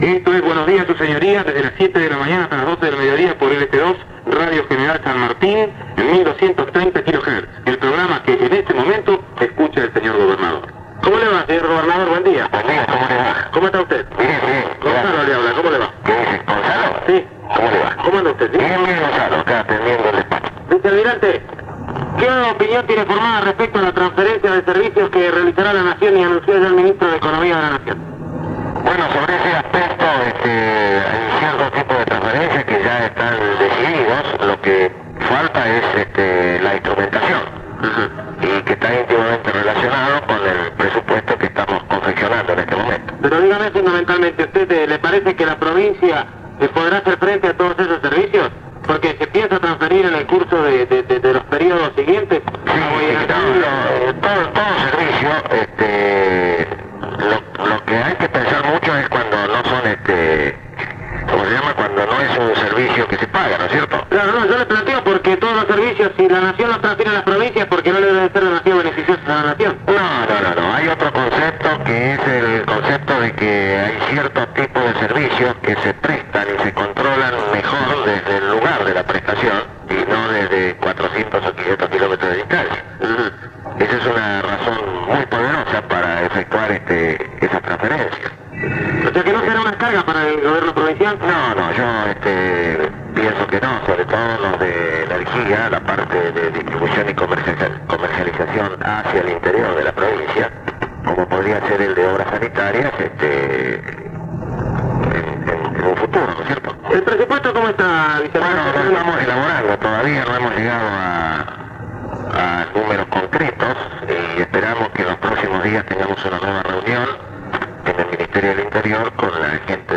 oa-en-cassettes-lt2-entrevista-al-gobernador-sf-vicealmirante-desimoni-1979.mp3